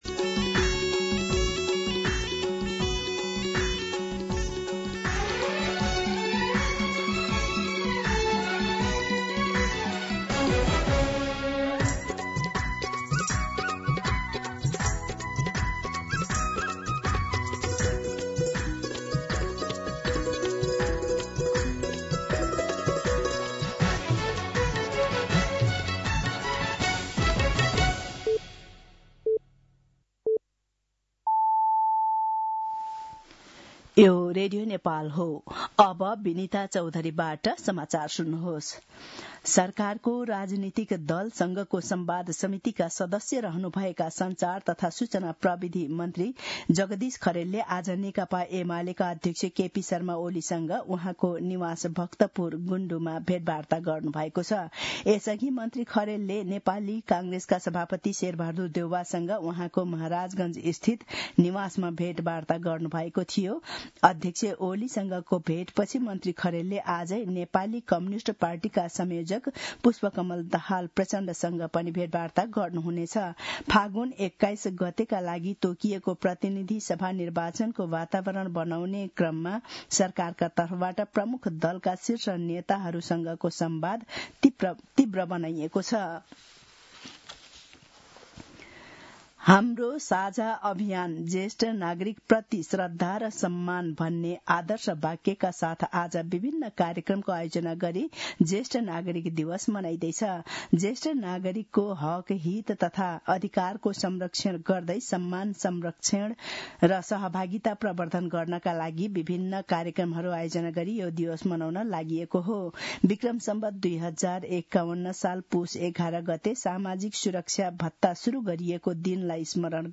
दिउँसो १ बजेको नेपाली समाचार : ११ पुष , २०८२